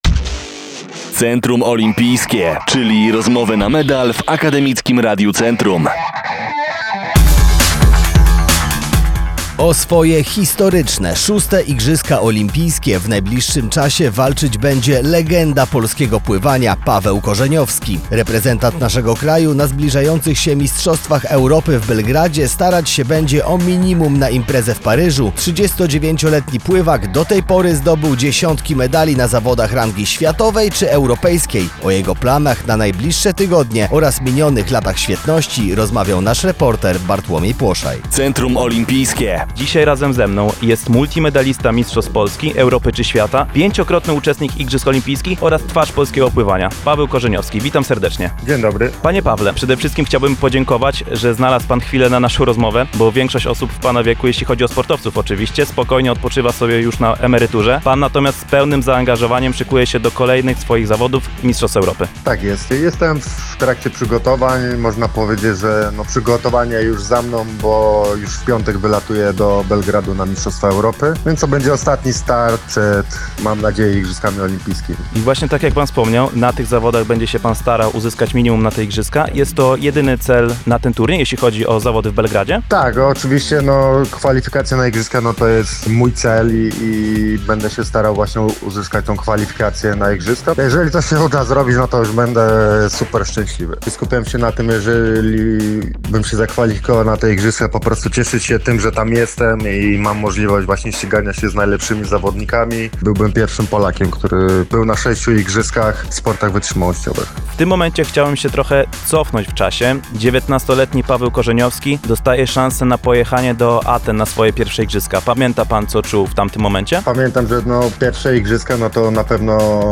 Paweł Korzeniowski był naszym gościem w programie „Centrum olimpijskie, czyli rozmowy na medal”, w którym powiedział jakie są jego plany na zbliżające się zawody Mistrzostw Europy oraz jak wspomina swoje starty na Igrzyskach Olimpijskich.